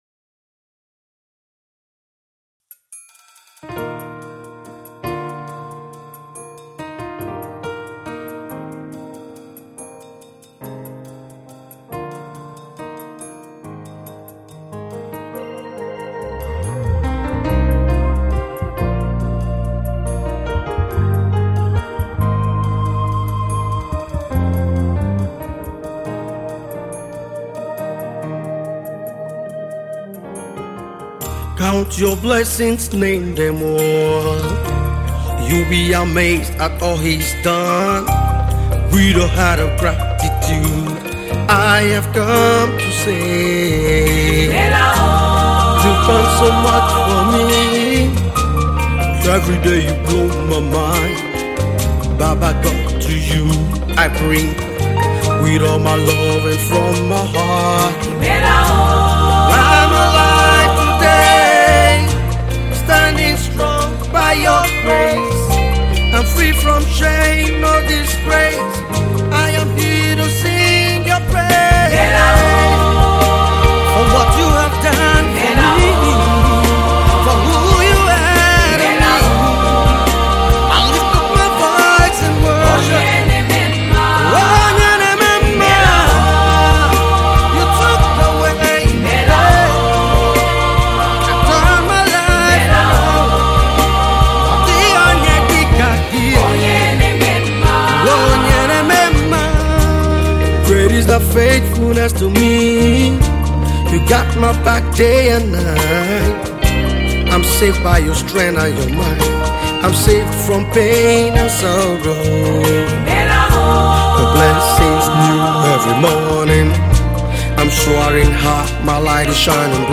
Contemporary Gospel singer